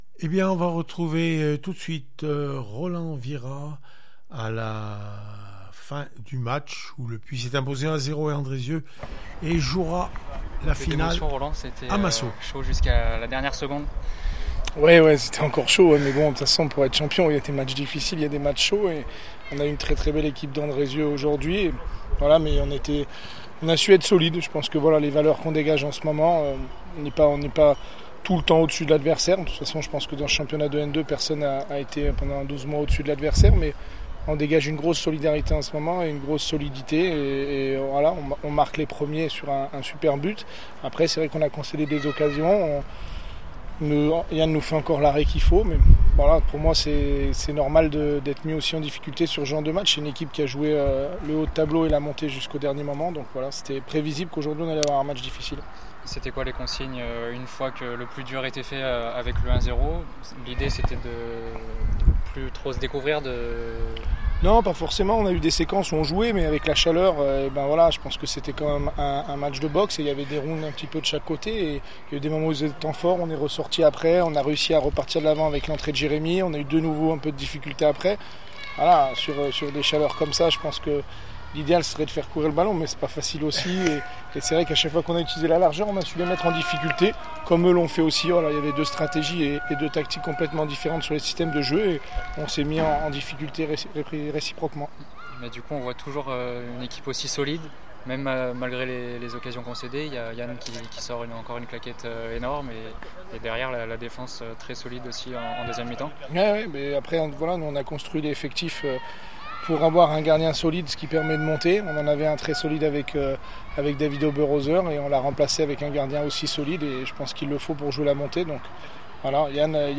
réactions après le match Andrezieux Boutheon fcfc 0-1 le Puy foot 43 aura